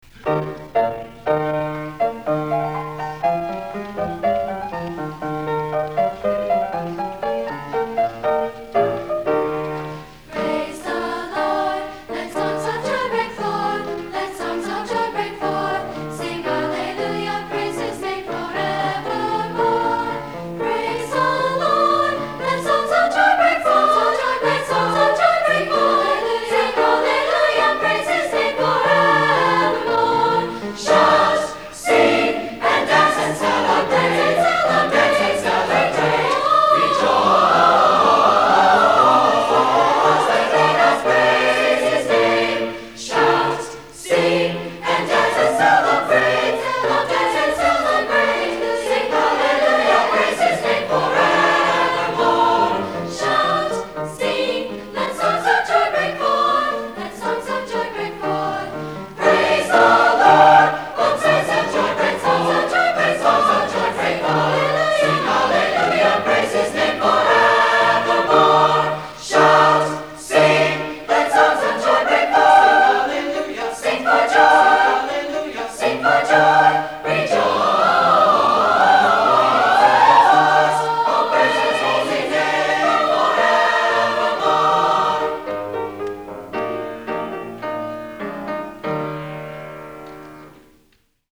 Spring Concert
CHS Auditorium